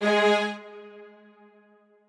strings3_10.ogg